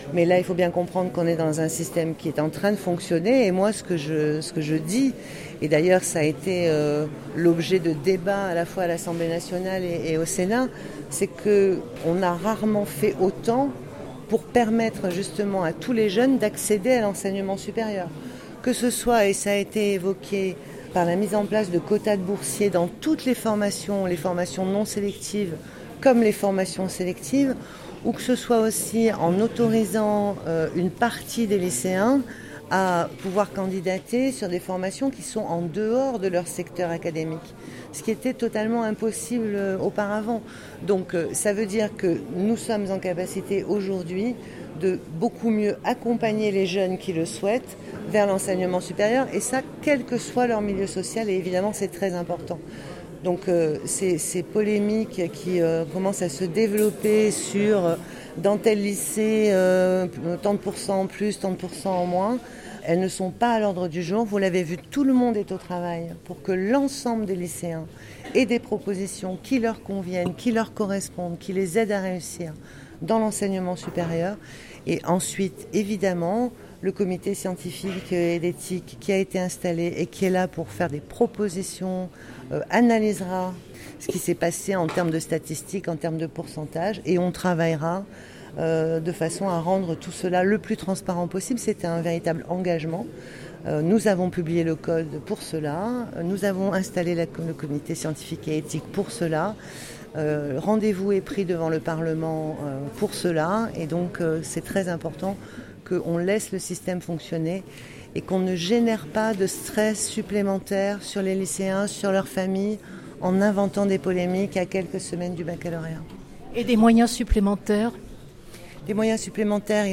Parcoursup: Entretien avec la ministre Frédérique Vidal: “On a rarement fait autant pour permettre à tous les jeunes d’accéder à l’Enseignement supérieur”